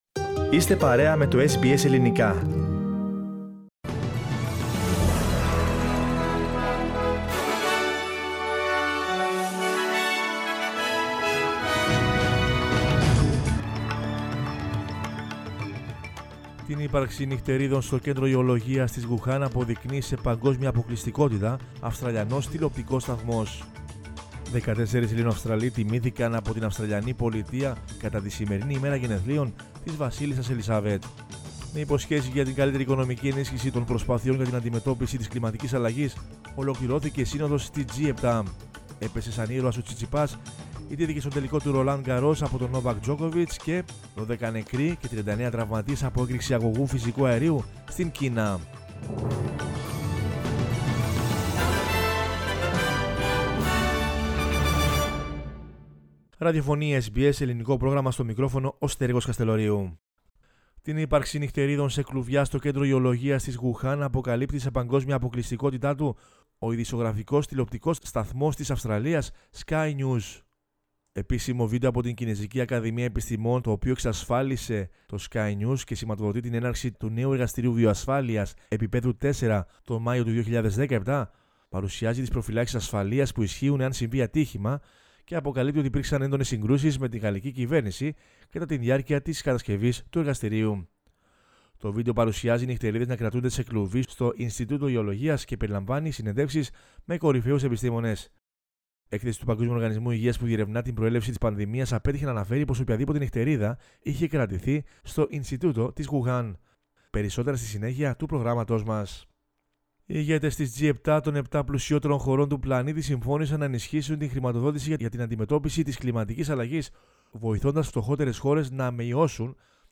News in Greek from Australia, Greece, Cyprus and the world is the news bulletin of Sunday 14 June 2021.